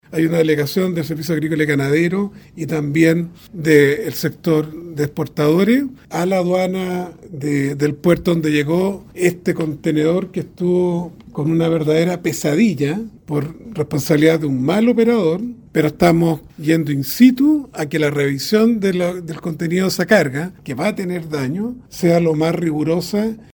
cerezas-ministro.mp3